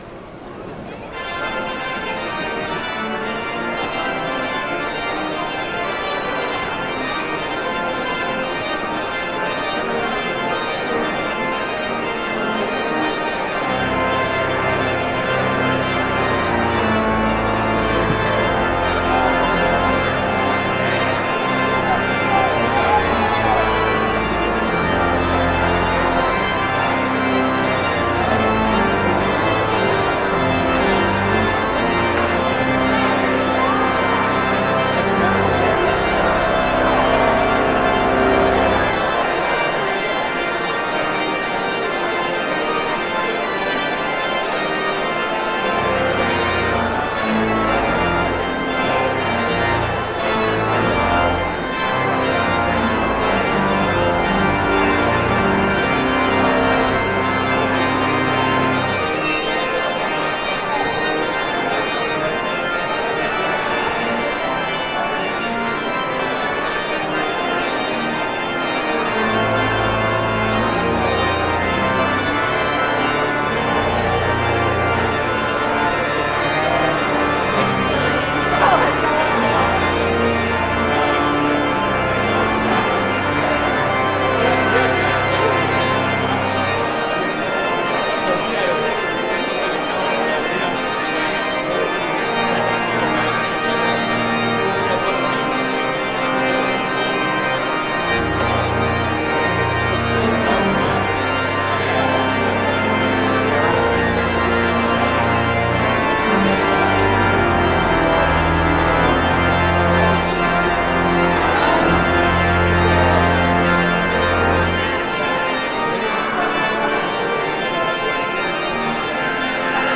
Postlude-44.mp3